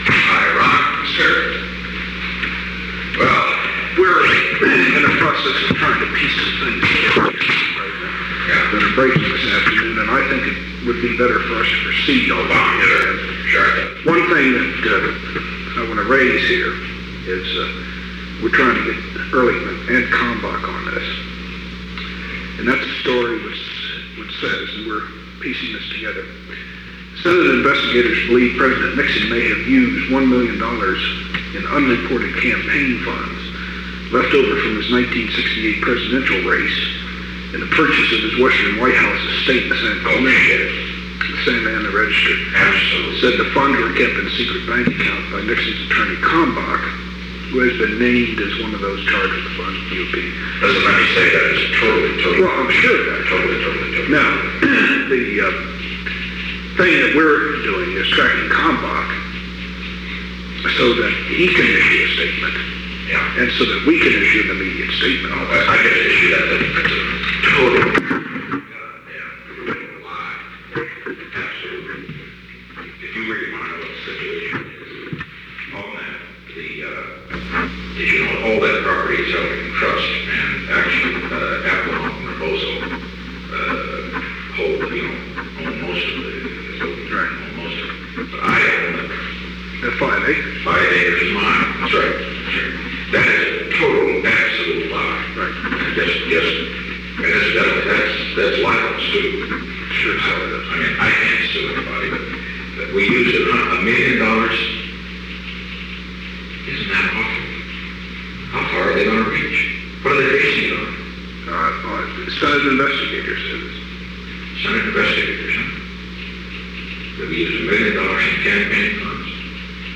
Secret White House Tapes
Conversation No. 917-40
Location: Oval Office
The President met with Ronald L. Ziegler.